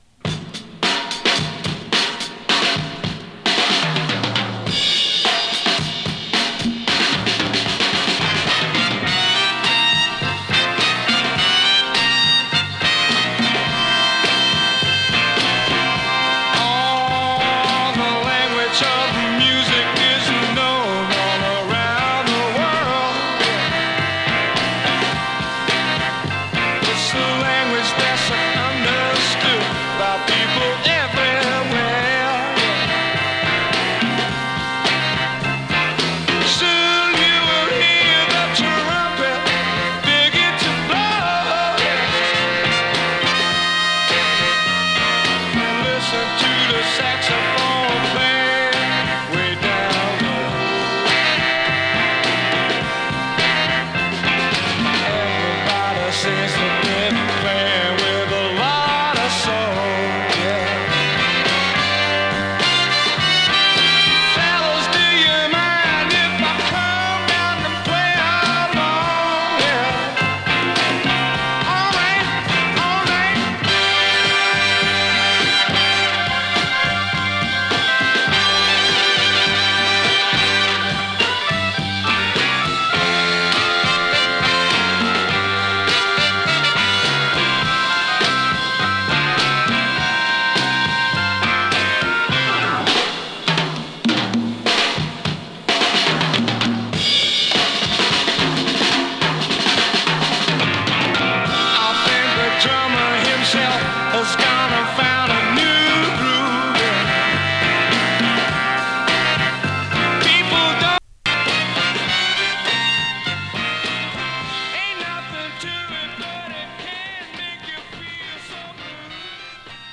強力なドラム・ブレイクで始まる